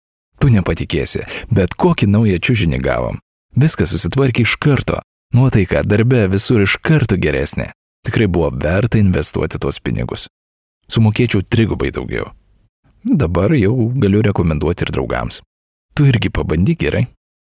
Lithuanian voice over artist native
Sprechprobe: Sonstiges (Muttersprache):